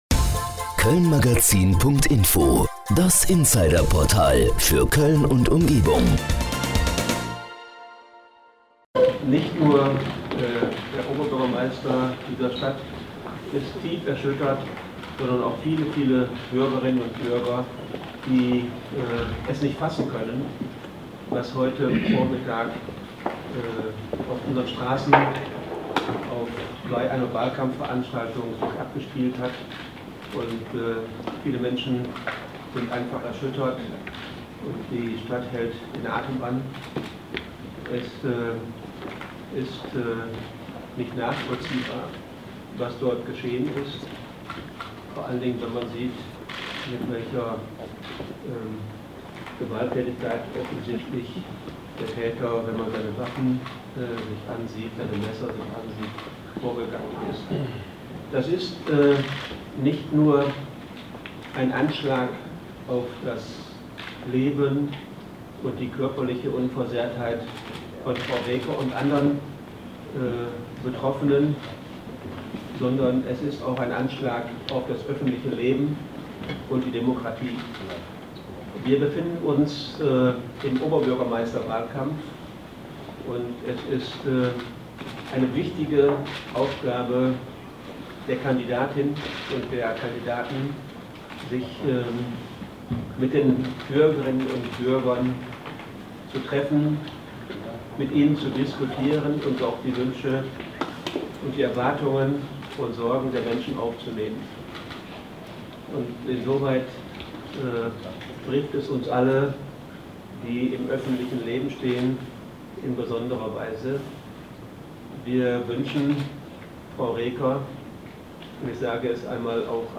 Audio_OB_Roters_zum_Anschlag_auf_Henriette_Reker.MP3